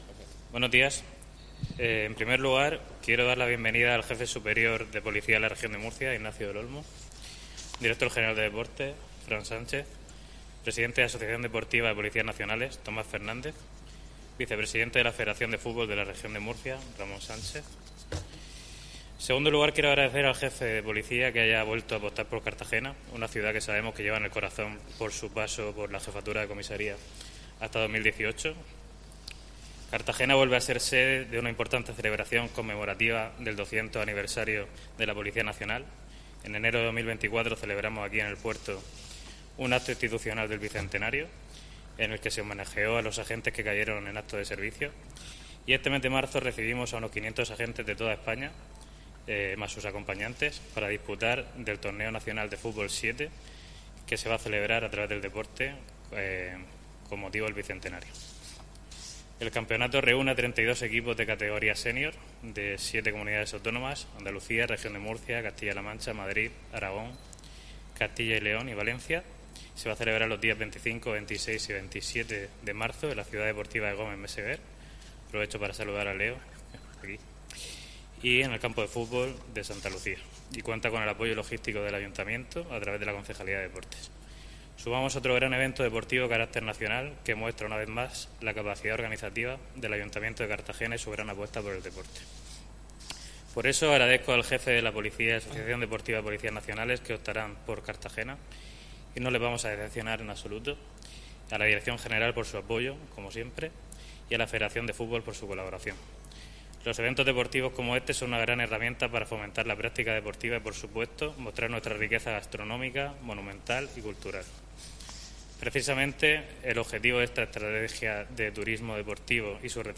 Enlace a Presentación del Campeontado de fútbol de Policías Nacionales